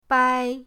bai1.mp3